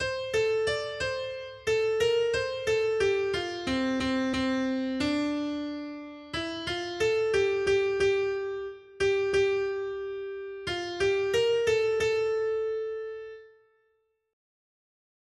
Noty Štítky, zpěvníky ol426.pdf responsoriální žalm Žaltář (Olejník) 426 Skrýt akordy R: Blaze každému, kdo se bojí Hospodina. 1.